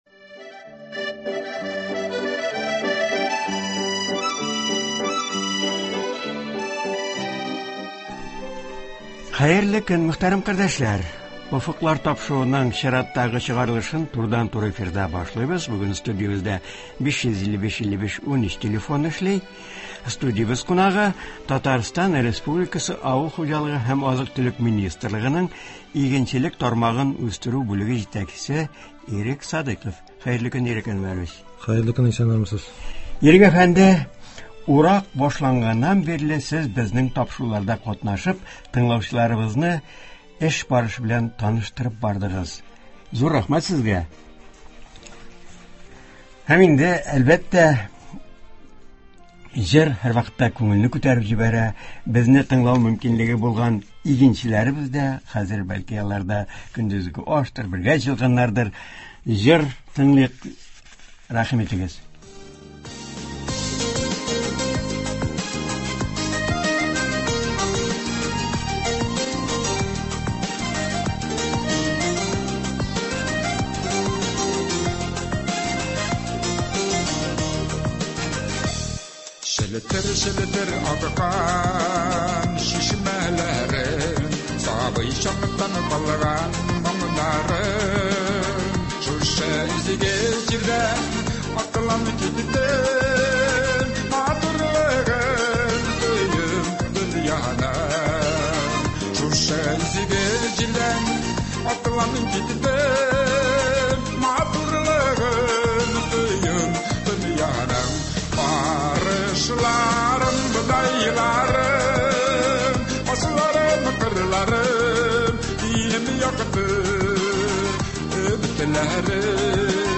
турыдан-туры эфирда